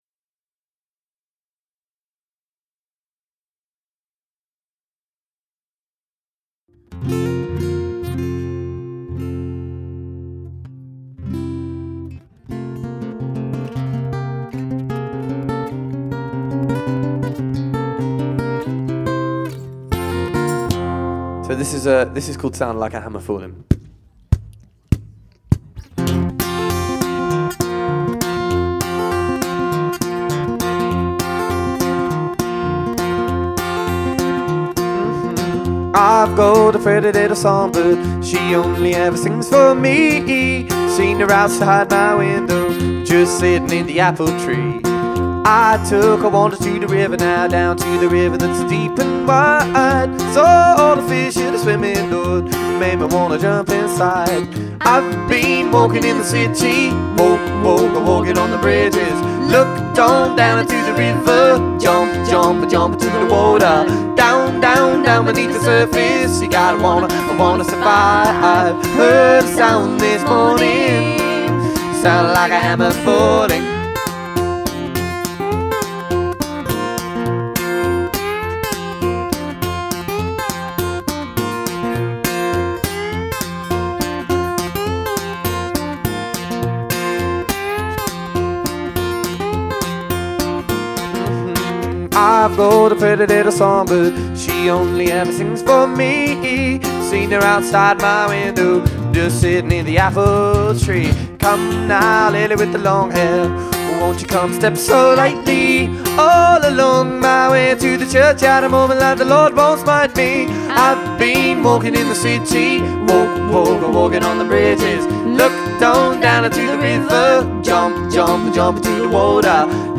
Recorded live at Rollright Fayre